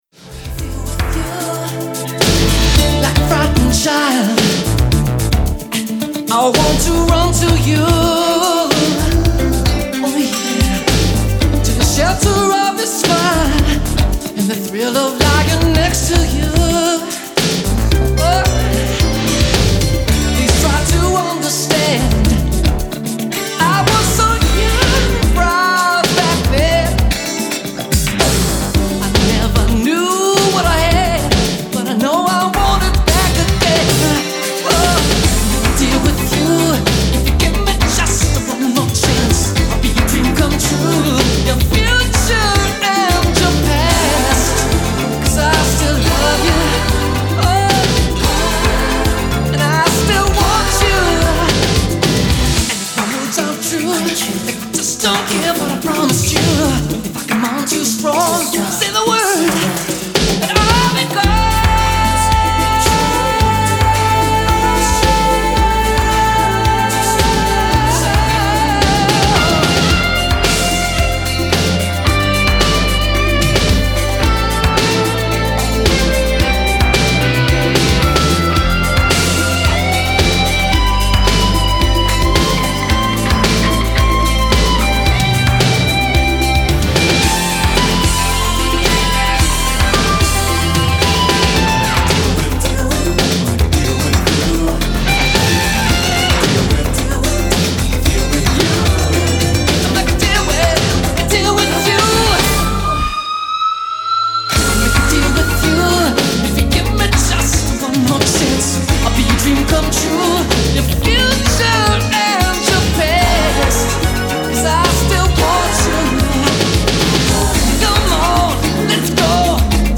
Performed: Drums and Bass